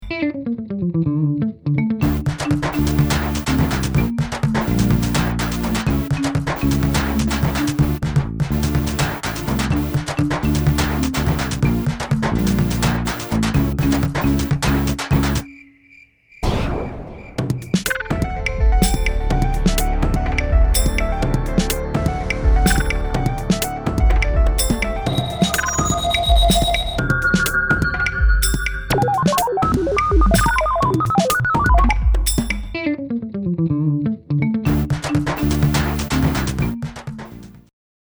(zonder zang)